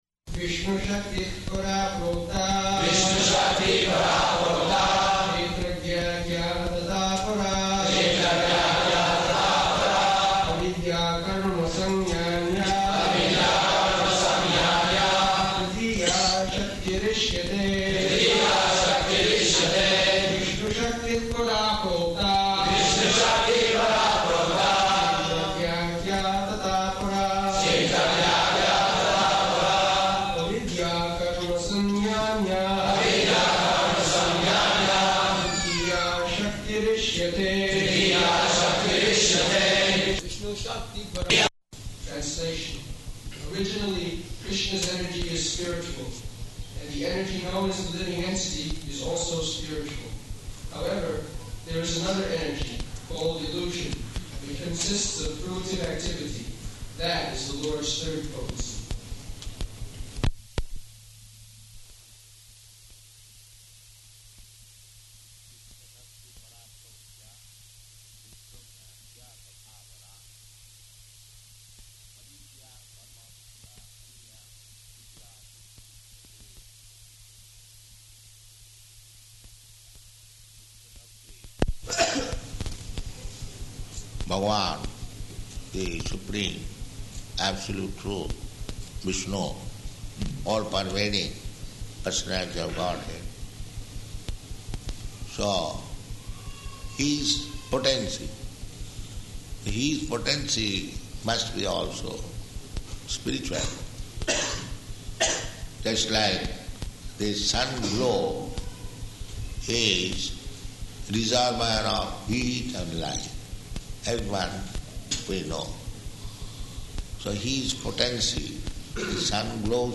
July 20th 1976 Location: New York Audio file